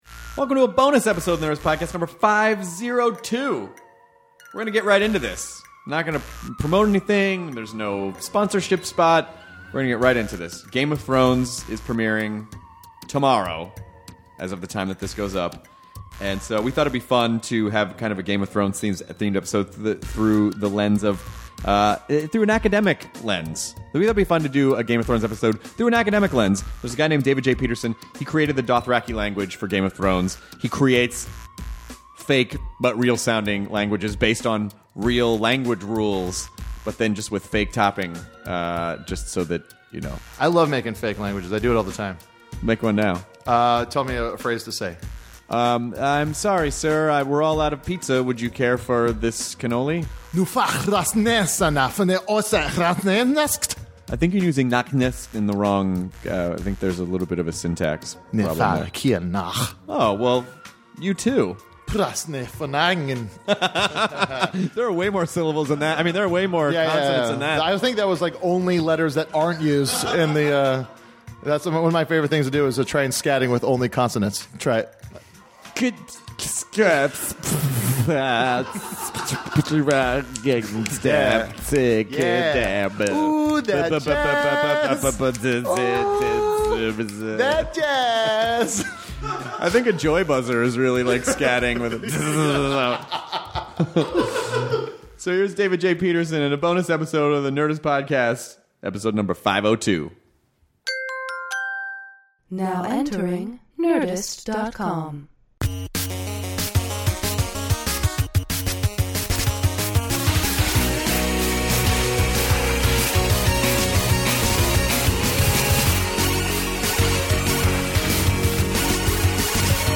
The guys sit down with linguist David J. Peterson to discuss how he makes up new languages, working on movies and shows like Game of Thrones and the history and evolution of dialects and languages around the world!